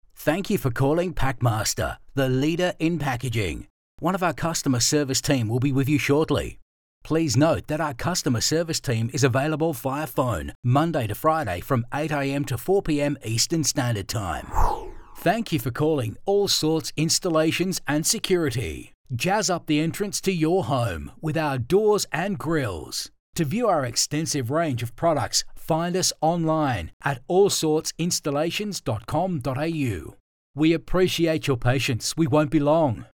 • On Hold
• Middle age male voiceovers
• Custom Voice Booth
• Microphone Neumann TLM 103